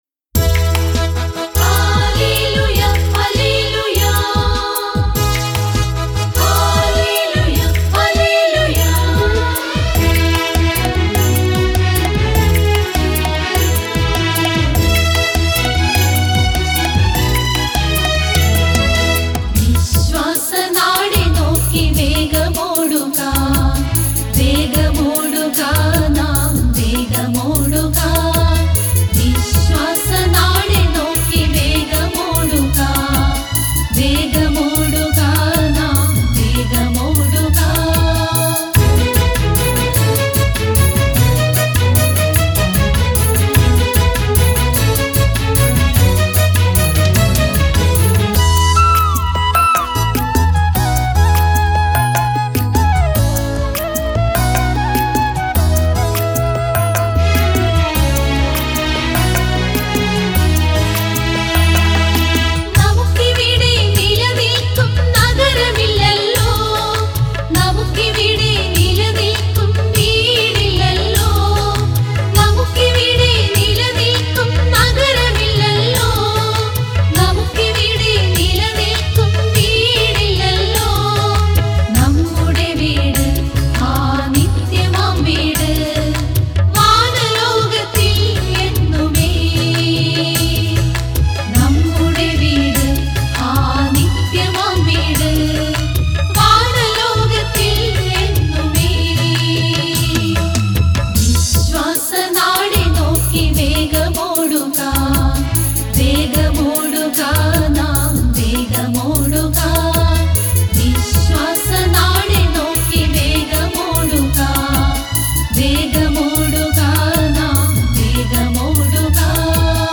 Singer : Chorus
Keyboard Sequence
Flute
Tabala & Percussion